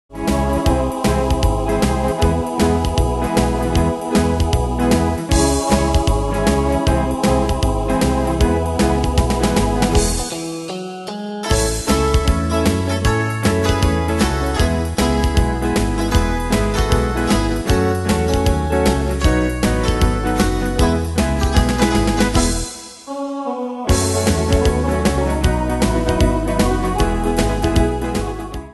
Style: Oldies Ane/Year: 1963 Tempo: 155 Durée/Time: 2.16
Danse/Dance: Triple Swing Cat Id.
Pro Backing Tracks